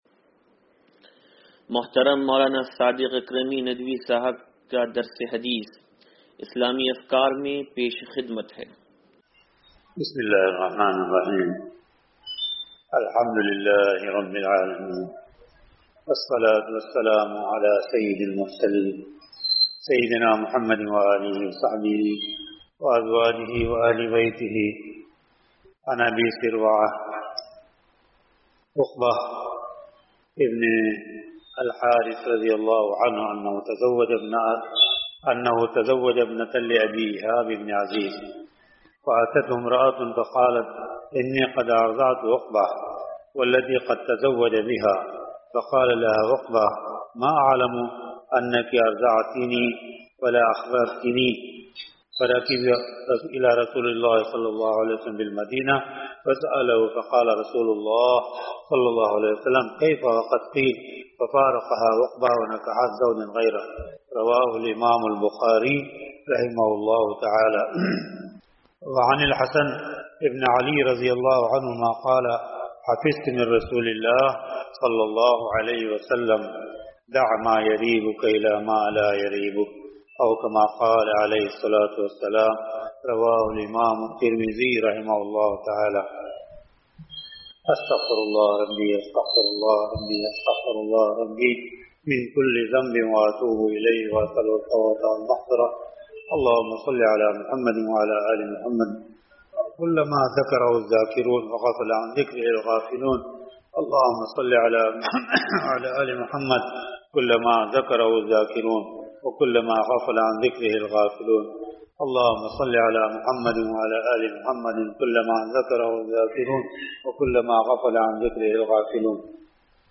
درس حدیث نمبر 0588
(سلطانی مسجد)